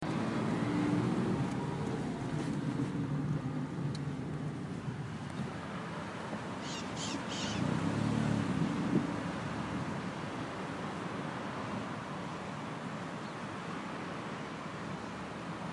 驱动器.m4a ( 驱动器 )
描述：Som deumainsuçãodeum carro com as janelas abertas，numa zona citadina，驾驶汽车开窗的声音
Tag: 公共 气氛 传递